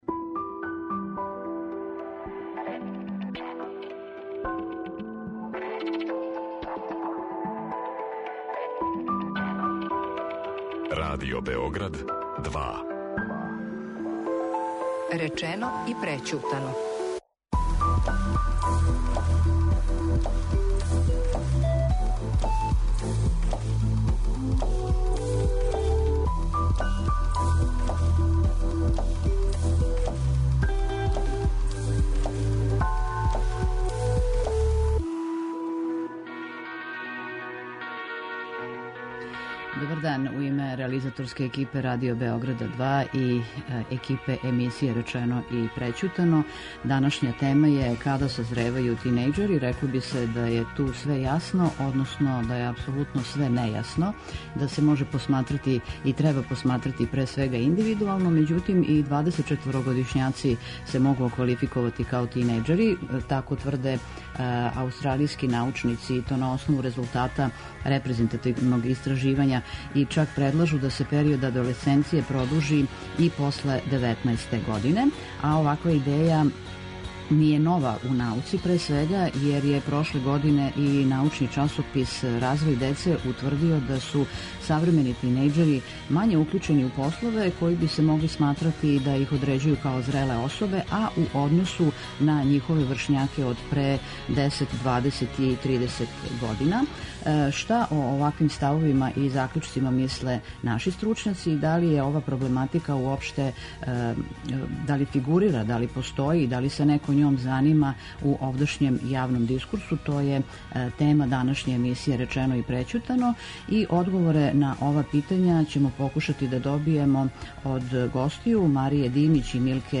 Саговорници